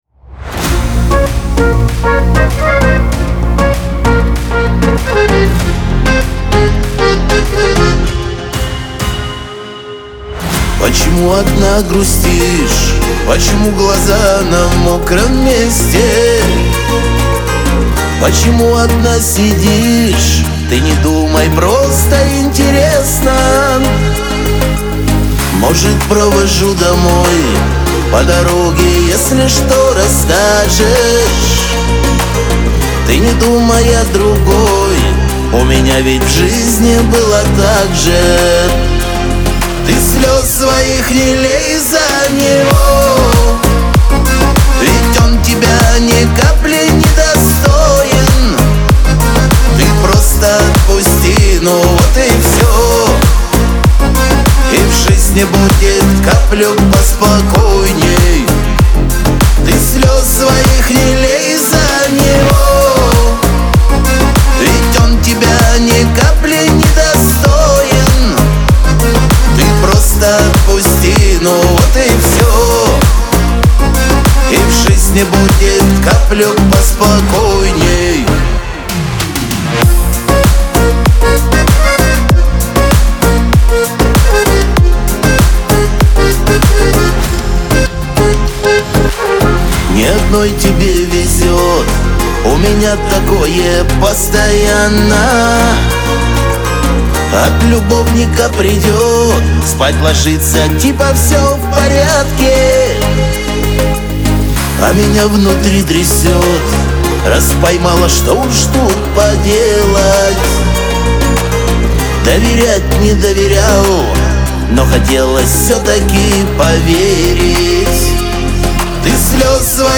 Лирика
Шансон
грусть